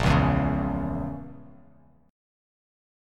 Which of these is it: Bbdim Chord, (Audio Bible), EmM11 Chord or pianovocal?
Bbdim Chord